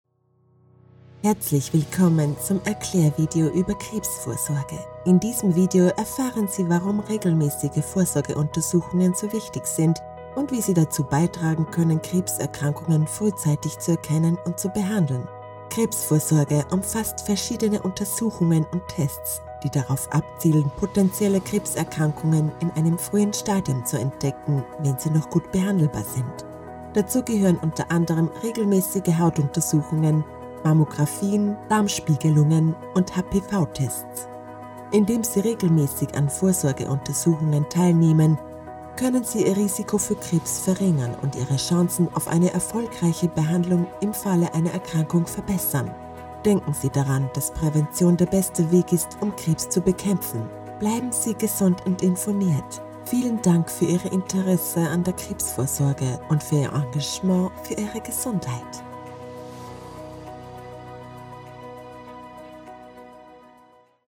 Female
Österreichisches Deutsch, warmherzig, freundlich, charmant, beruhigend, energisch
Explainer Videos
Explainer Video Cancerscreening